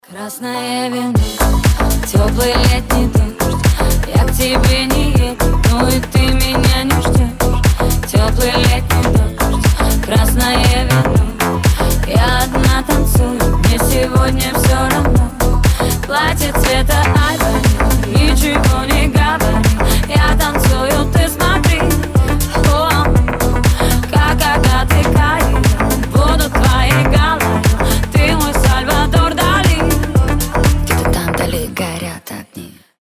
• Качество: 320, Stereo
Club House